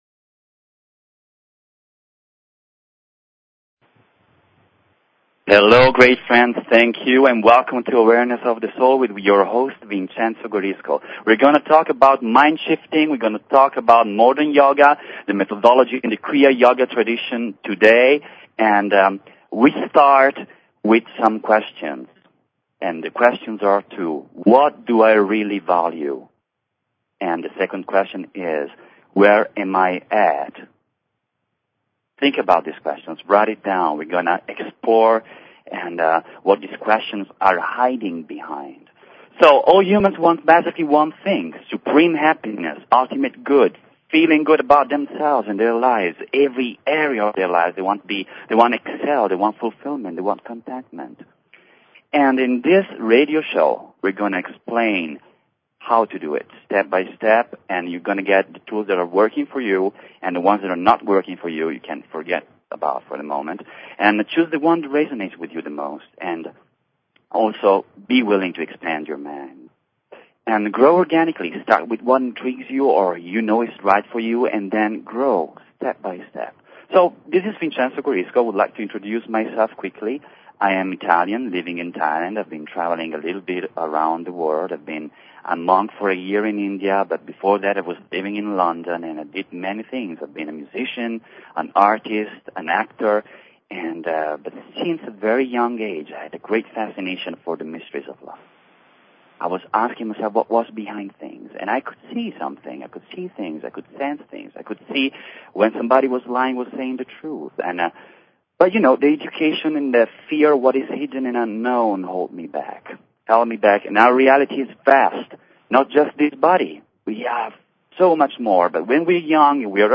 Talk Show Episode, Audio Podcast, Awareness_of_the_Soul and Courtesy of BBS Radio on , show guests , about , categorized as